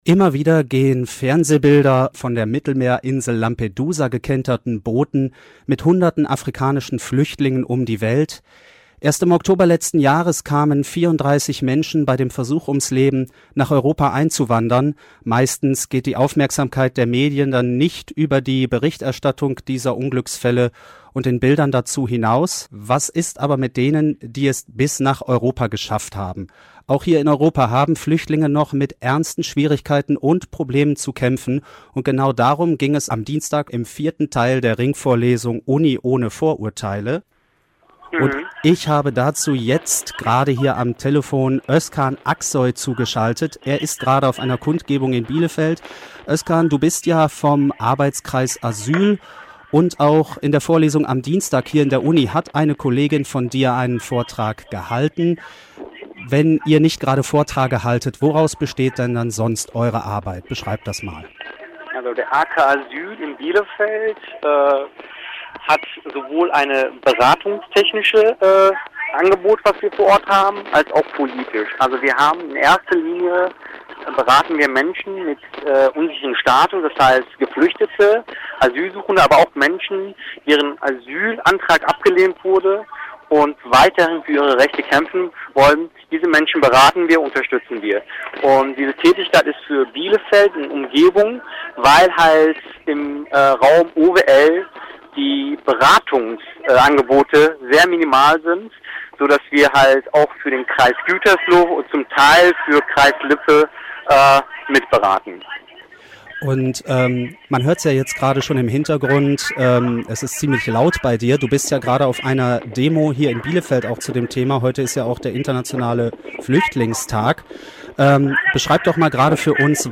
Radio Hertz: Telefoninterview: Flüchtlinge in Deutschland und OWL